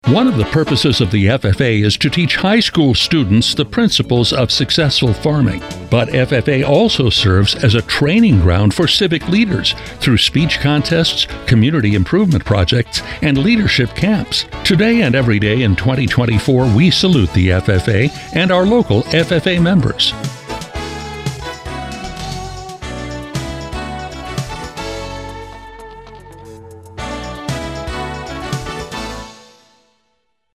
20 :30-second features